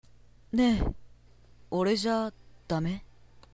セリフ
1番目の台詞は、好きな子に彼氏がいても、お色気でアタックする感じです。